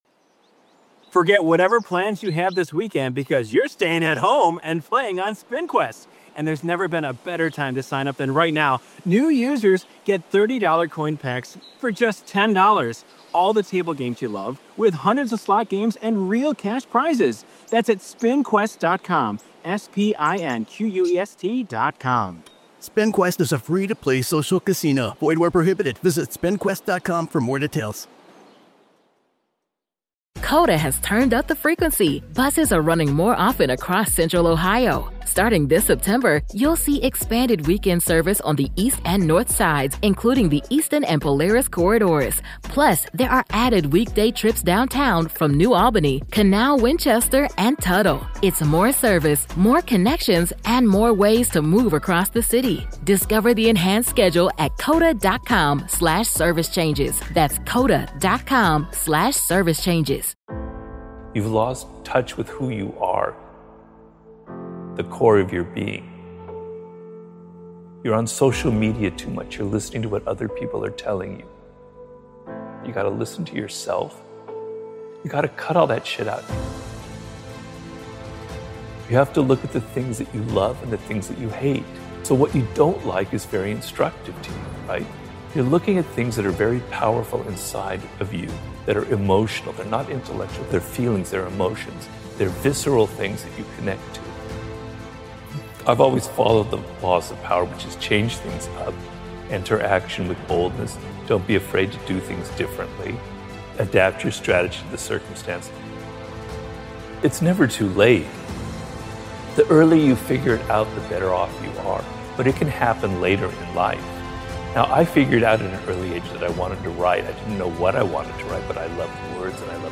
Speaker: Robert Greene Robert Greene is an American author of books on strategy, power, and seduction.